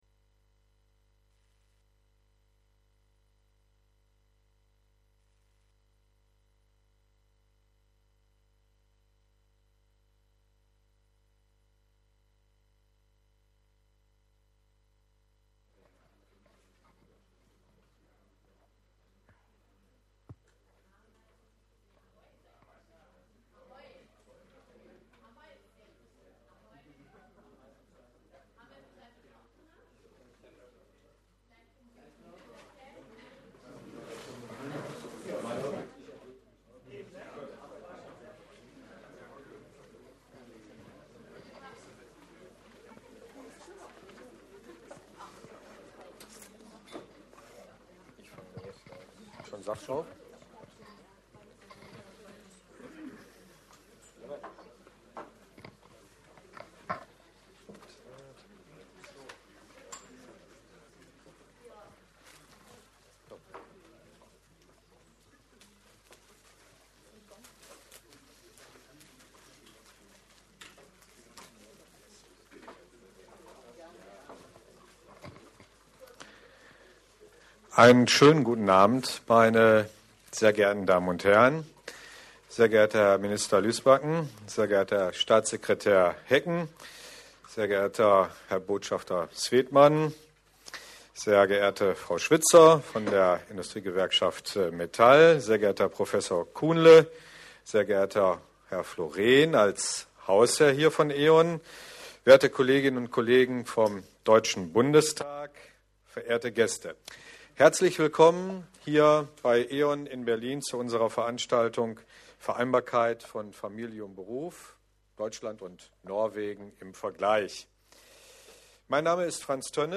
Aufzeichnung der Podiumsdiskussion [MP3]